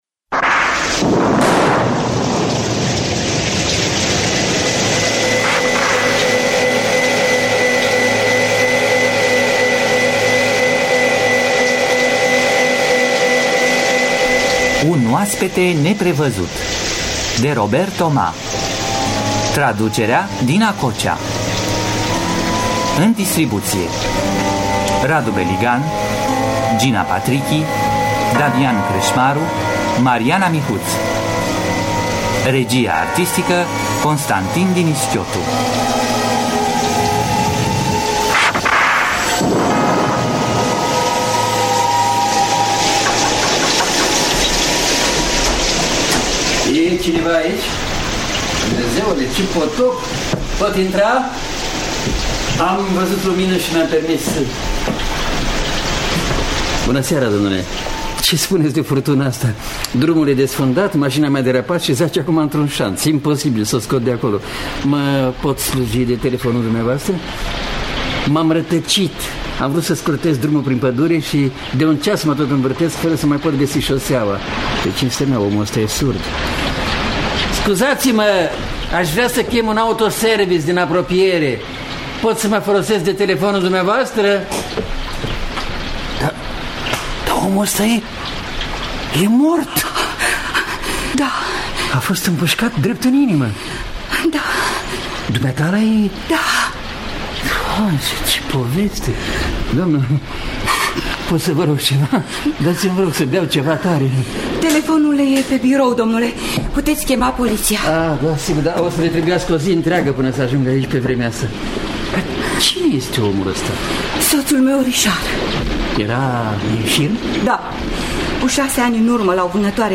Robert Thomas – Un Oaspete Neprevazut (1991) – Teatru Radiofonic Online